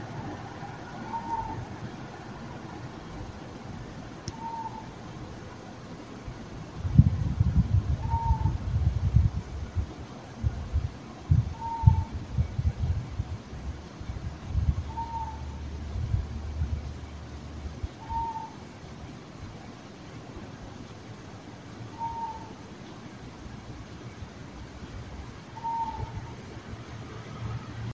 ناله می کند. با فاصله های یکسان. تُن صدای یکنواخت.
صدایِ همسایه ی جدید